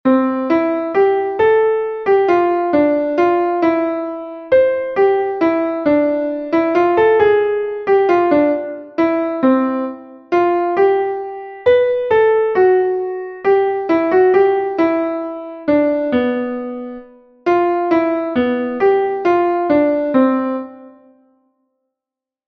Entoación a capella
entonacioncapela10..5mp3.mp3